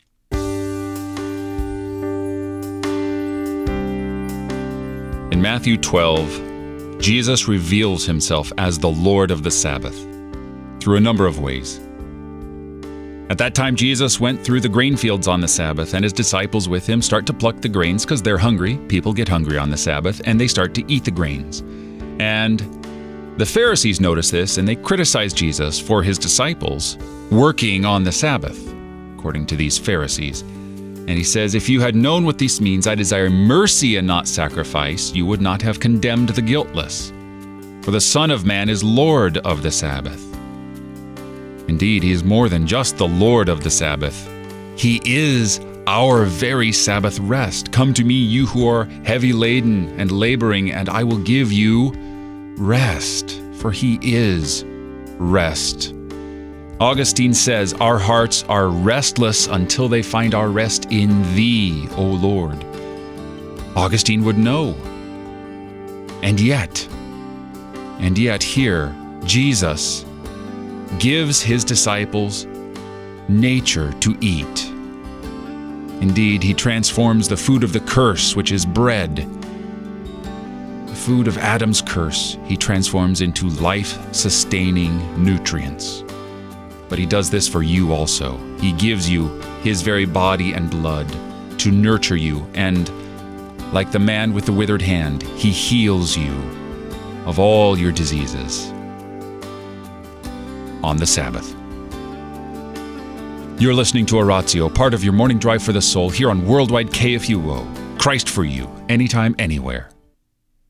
and also gives a short meditation on the day’s scripture lessons.